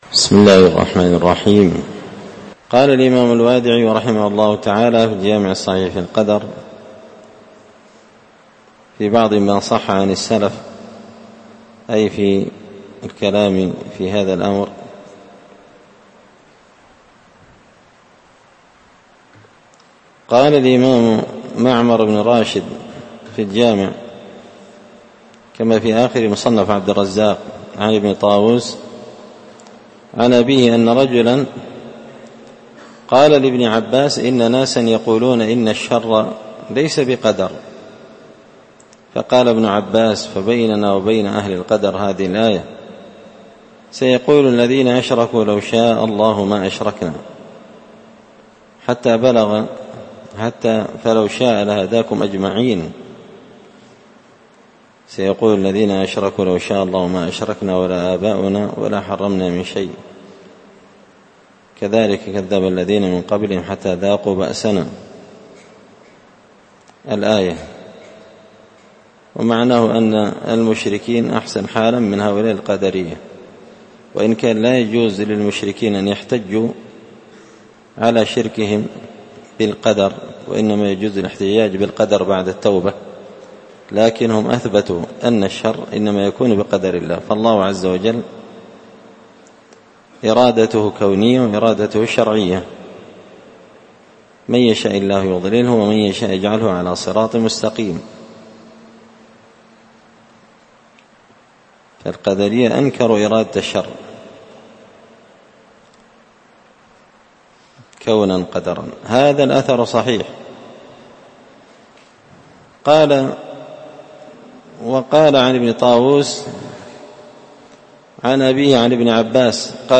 الدرس 130 فصل في ماصح عن السلف في القدر
دار الحديث بمسجد الفرقان ـ قشن ـ المهرة ـ اليمن